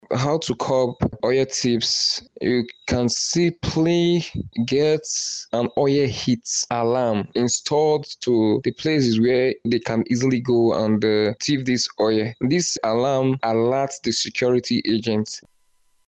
In an interview with Boss Radio News